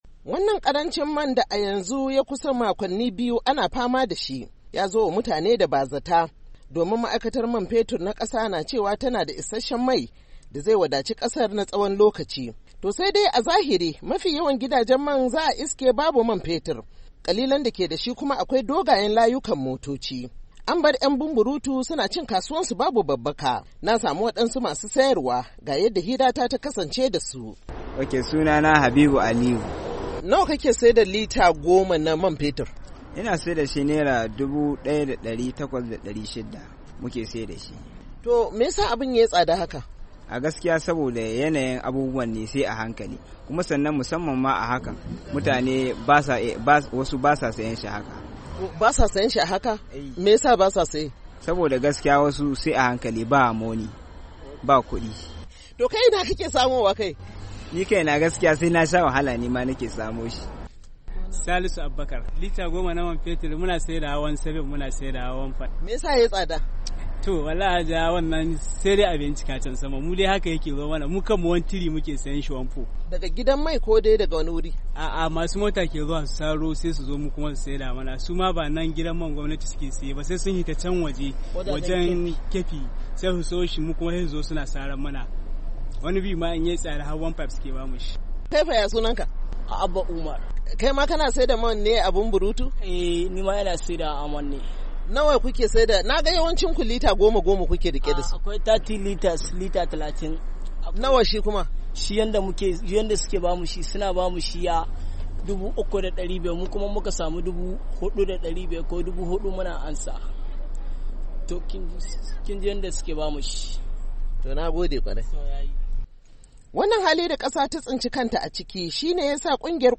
ta zanta da wasu masu sayar da Man